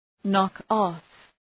knock-off.mp3